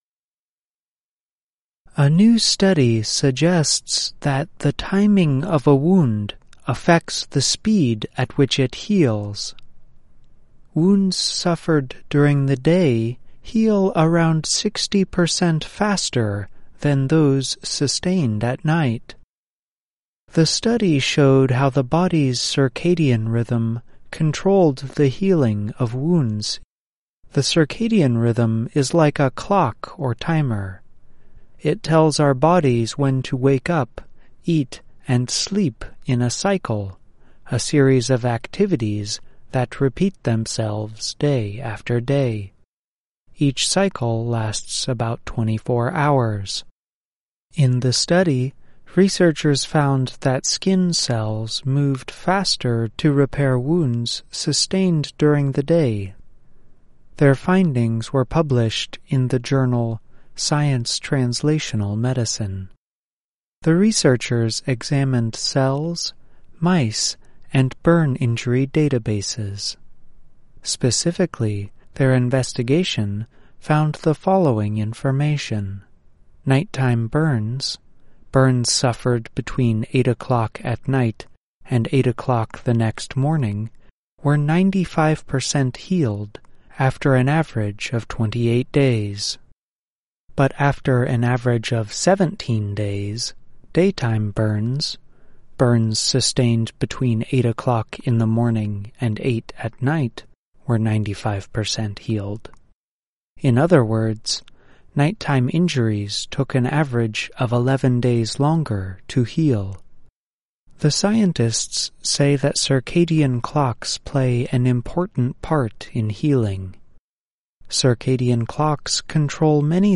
慢速英语:日间伤口愈合更快